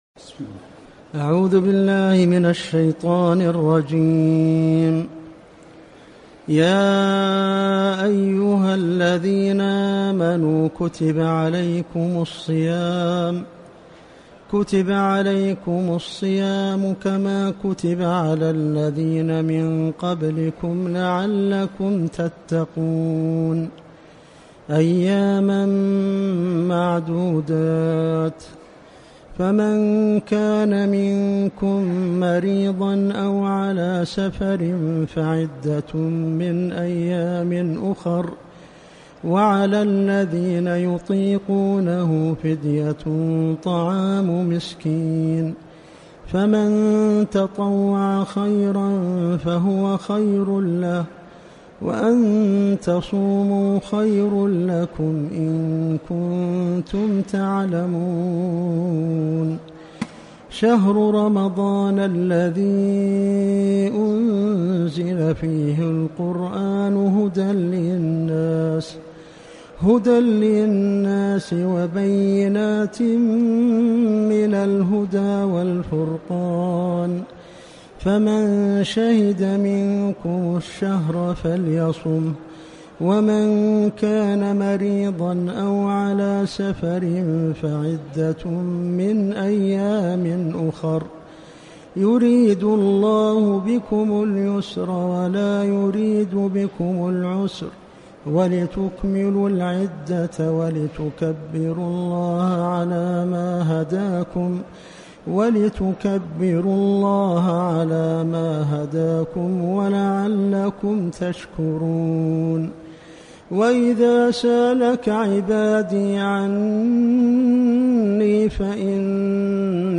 دروس تفسير آيات الصيام(الحرم المدني)-درس (3)-قوله سبحانه(وعلى الذين يطيقونه)